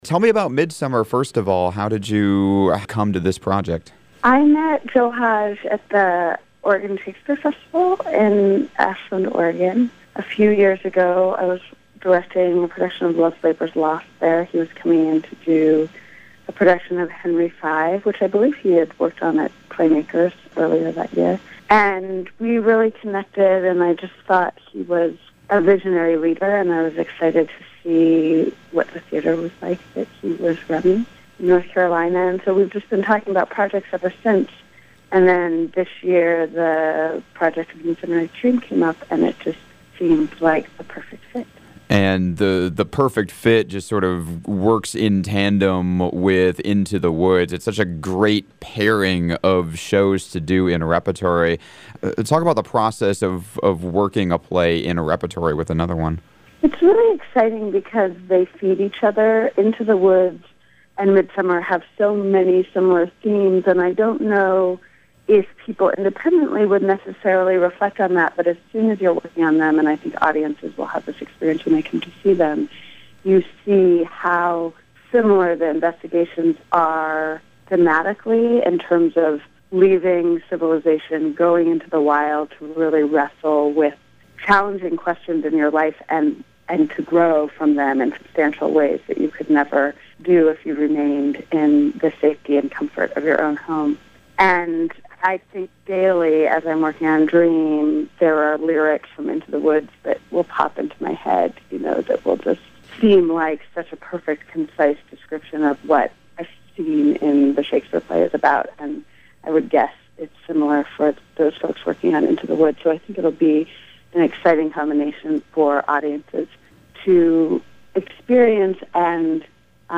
on the air this week…